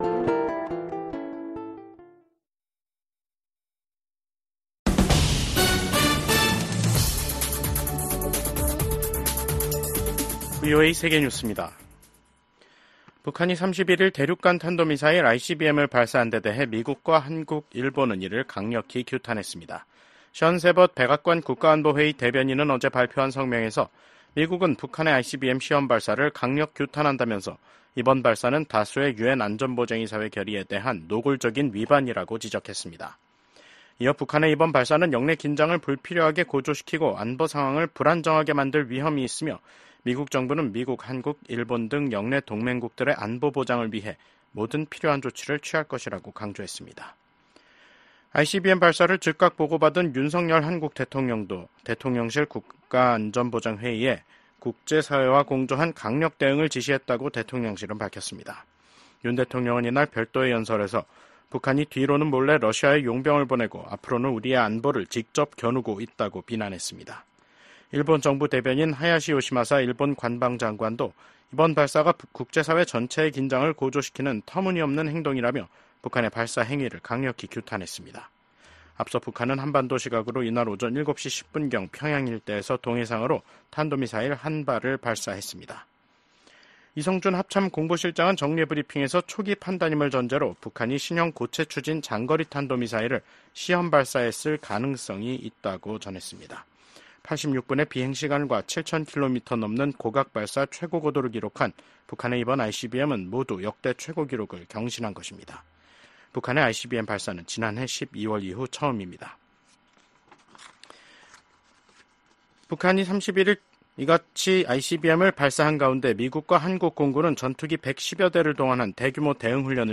VOA 한국어 간판 뉴스 프로그램 '뉴스 투데이', 2024년 10월 31일 2부 방송입니다. 북한이 미국 대선을 닷새 앞두고 동해상으로 대륙간탄도미사일(ICBM)을 발사했습니다. 미국 백악관이 북한의 대륙간탄도미사일(ICBM) 발사를 규탄하고 북한에 불안정한 행동을 중단할 것을 촉구했습니다. 미국과 한국의 국방장관이 북한군의 러시아 파병을 한 목소리로 강력하게 규탄했습니다.